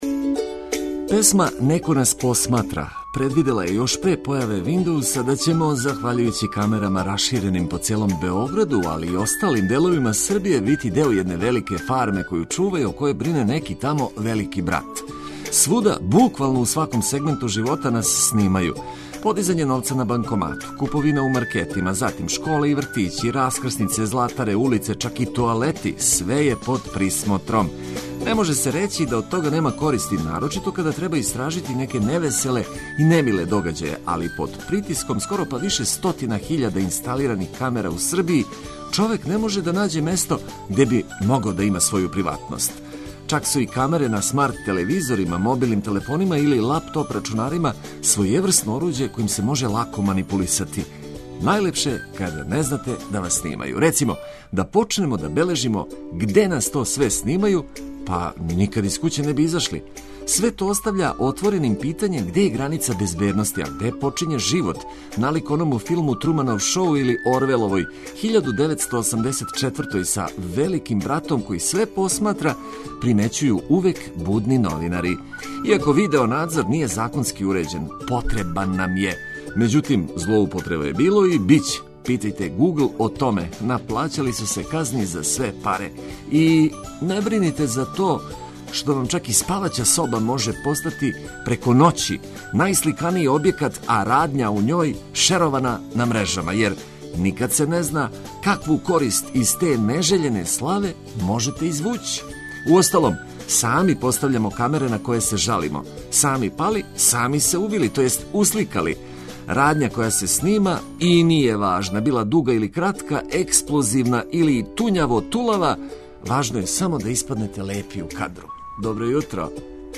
Хитови и свеже информације као прикључак новом дану - то је најукуснији и најједноставнији рецепт за сласни радио-доручак.